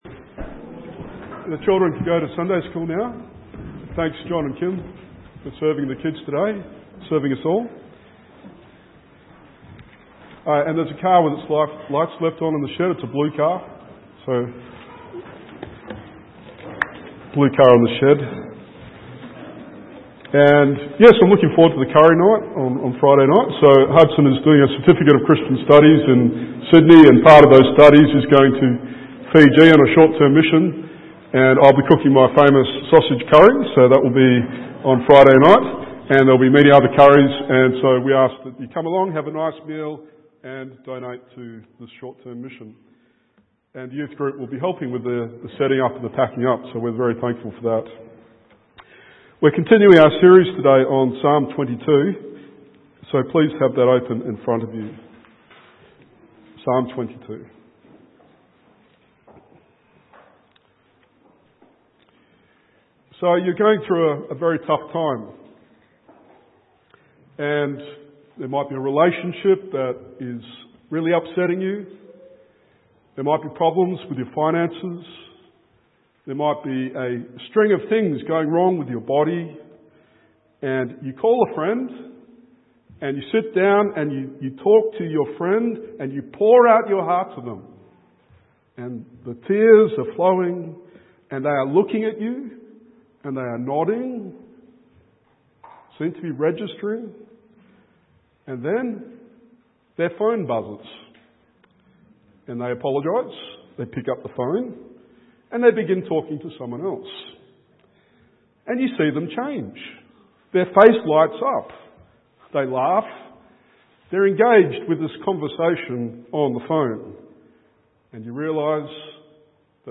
Sermon Series - Psalm 22 | Cornerstone Hobart